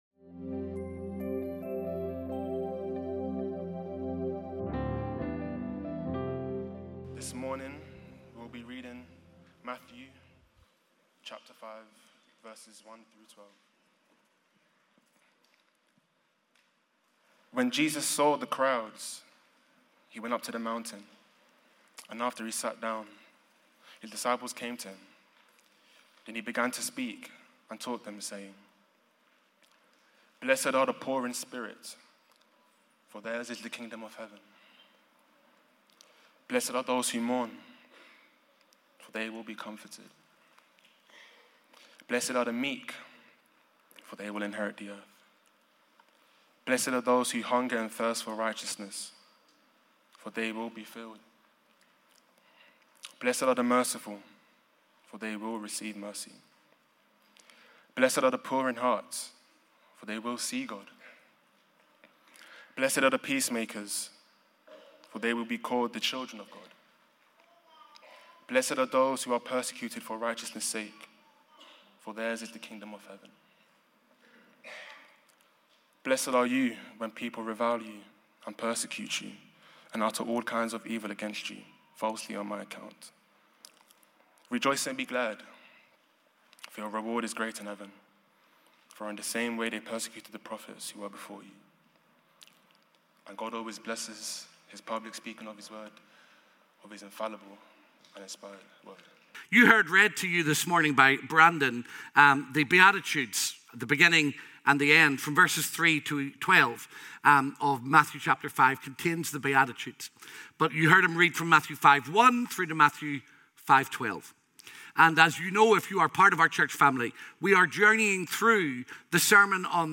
_Sermon Series